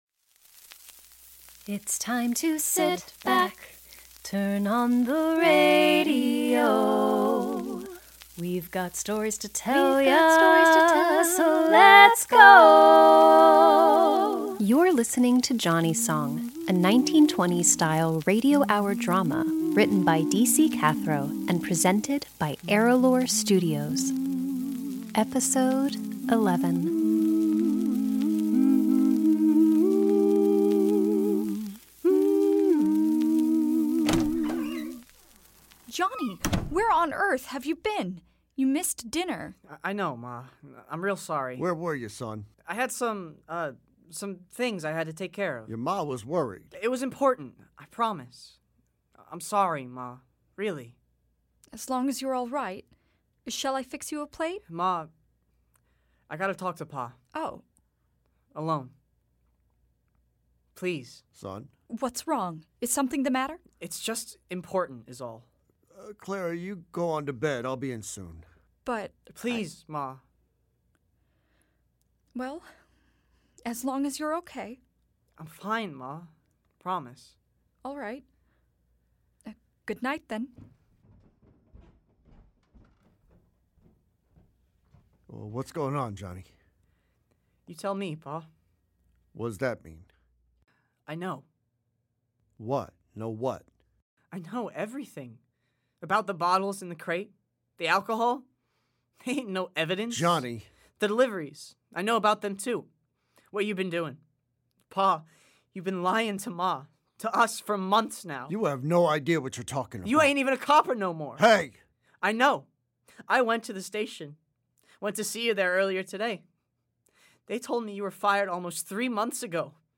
Johnnie's Song: A 1920s Style Radio Hour Drama Podcast - Episode 11: All Grown Up | Free Listening on Podbean App